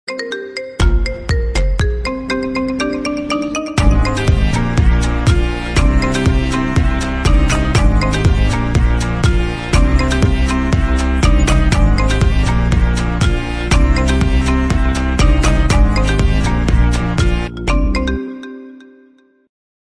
Funk Carioca